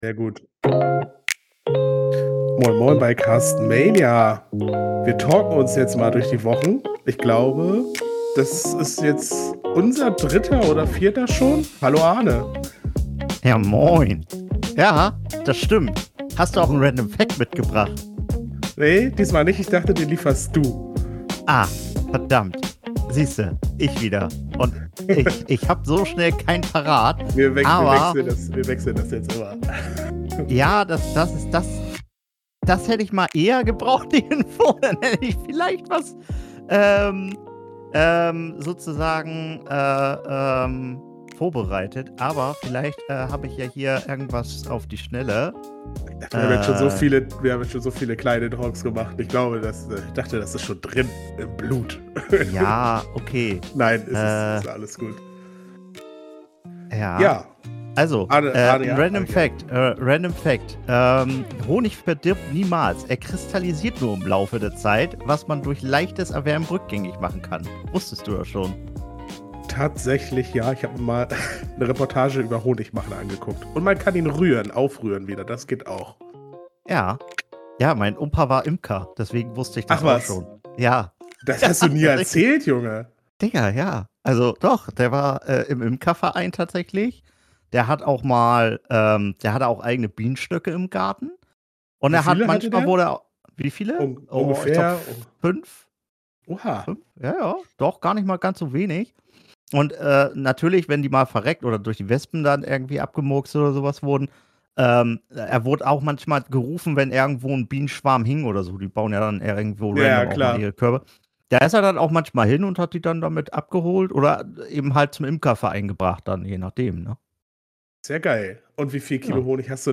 im Gespräch über Bienen und Junggesellenabschiede.